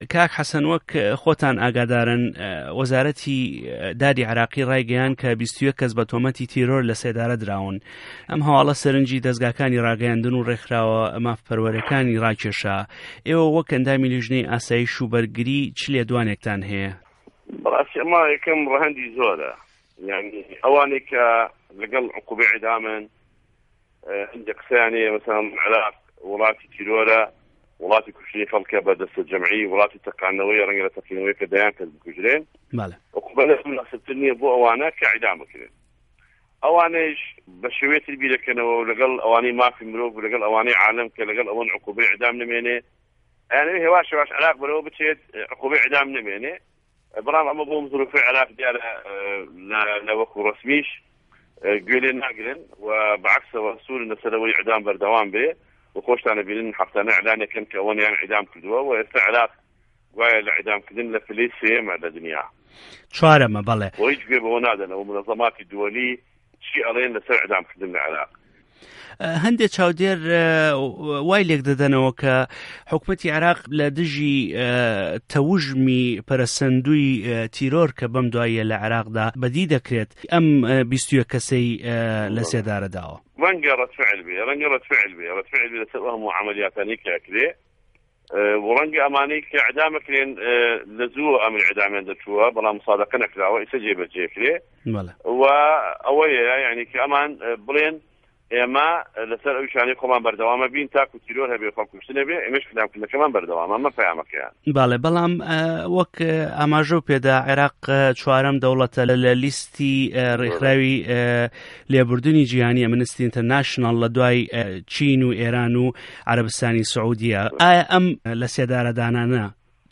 وتووێژی حه‌سه‌ن جیهاد